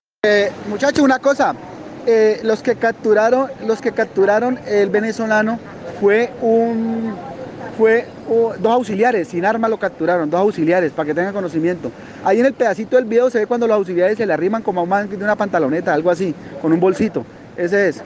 El Comandante de la Policía se refirió al lamentable hecho de intolerancia.